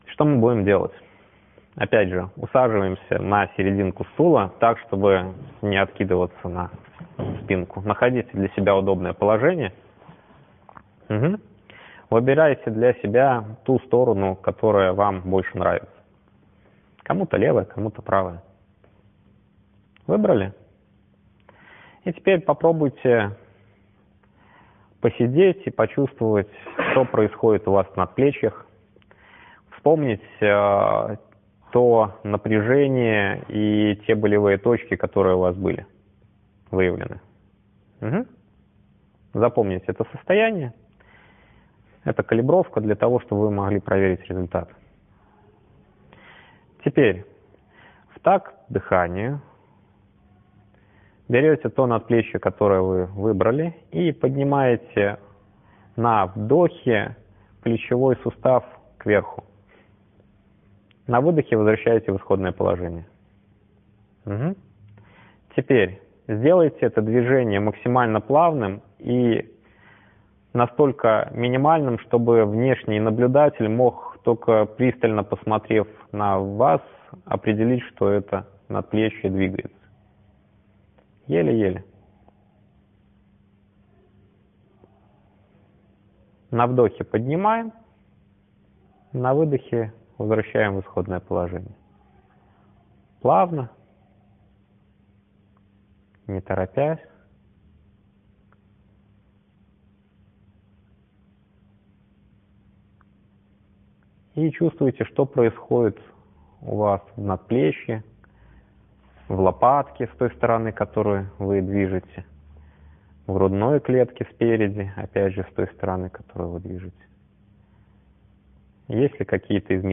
Урок на осознавание мышц надплечий.